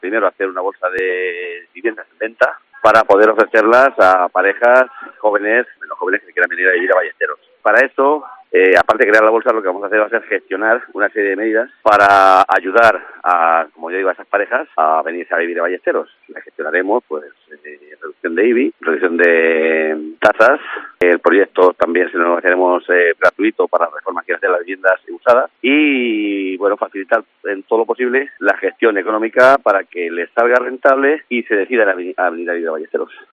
Juan Carlos Moraleda, alcalde Ballesteros de Calatrava